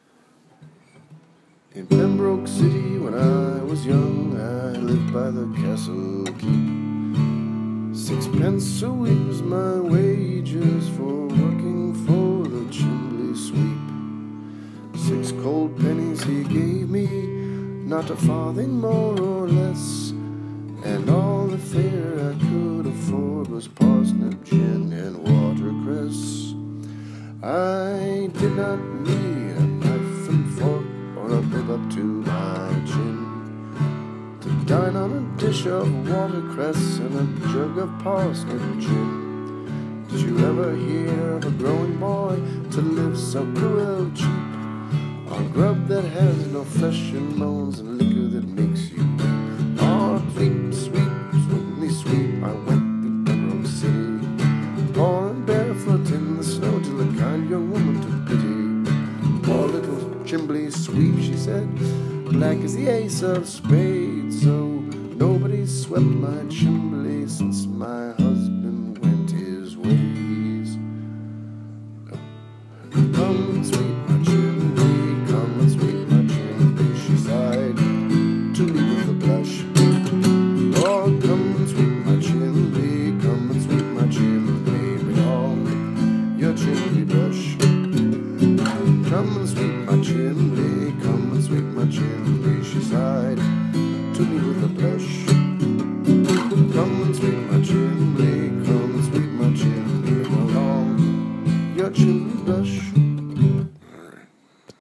Mr. Waldo’s song, a jig, I thought would be a breeze.